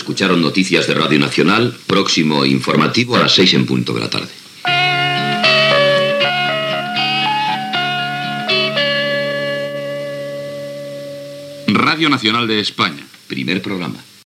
Comiat de l'informatiu, sintonia i identificació com a Primer Programa.